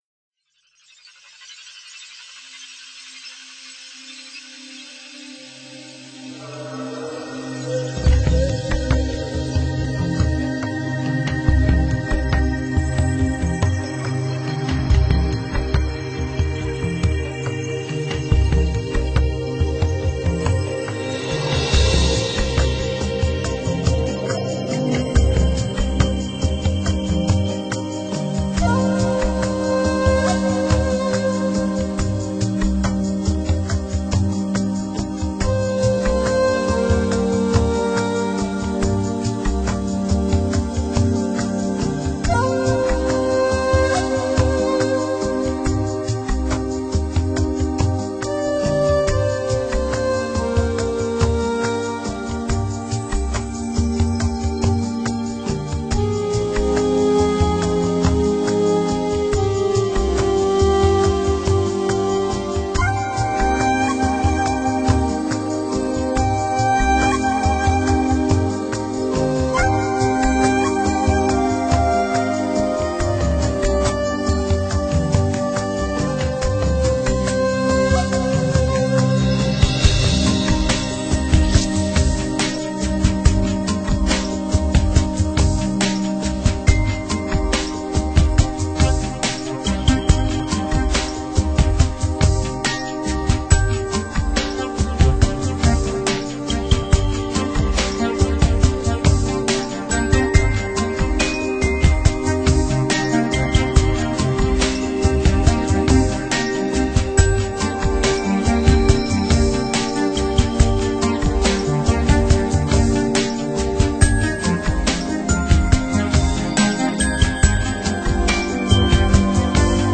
专辑语言：纯音乐
尺八悠扬的旋律响起，落日的余辉，撒在森林的角落，把满地的落叶染得通红。
金属片的振响沉淀在尺八低沉而圆润的节奏中，电子音乐的旋律，哄托起尺入的低郁。